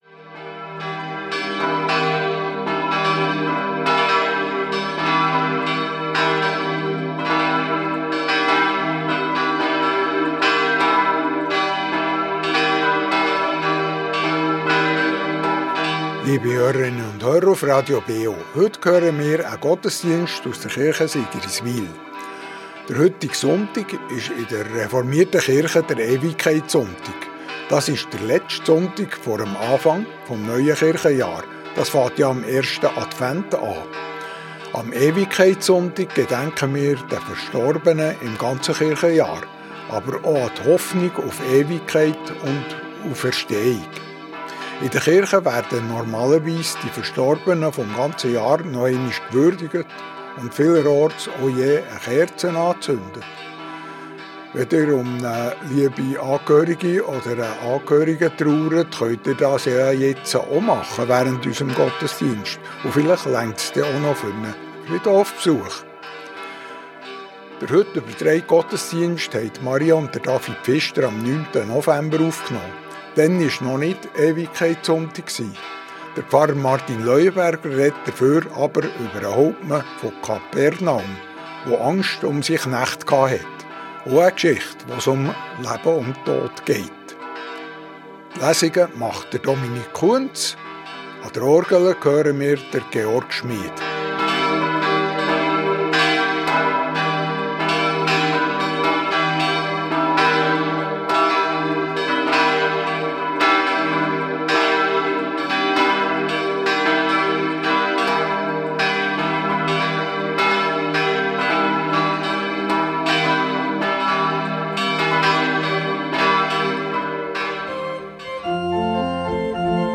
Reformierte Kirche Sigriswil ~ Gottesdienst auf Radio BeO Podcast